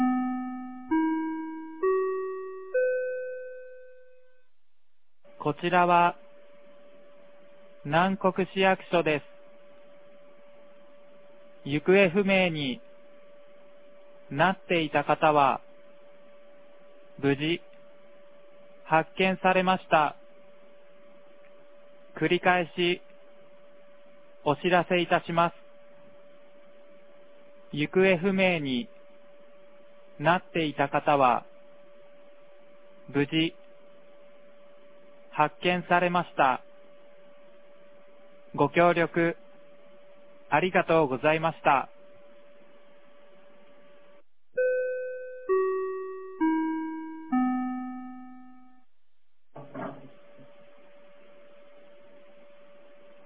2023年08月30日 15時35分に、南国市より放送がありました。